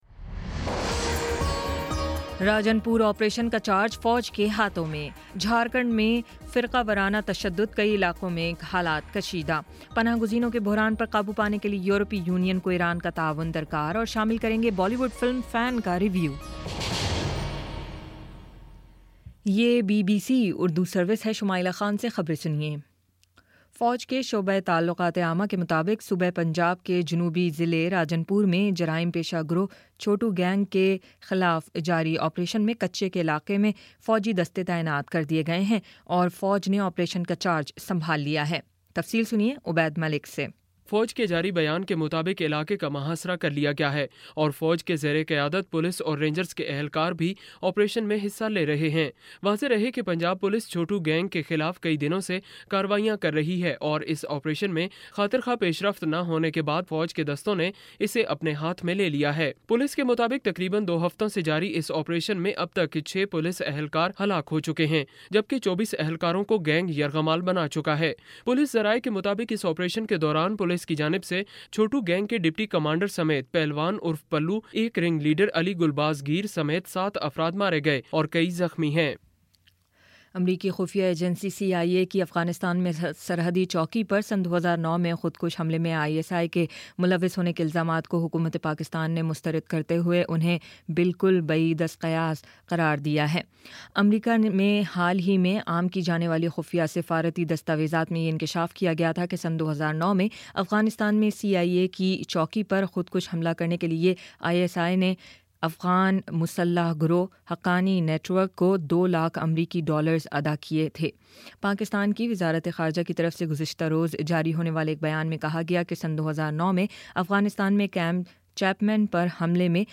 اپریل 06 : شام پانچ بجے کا نیوز بُلیٹن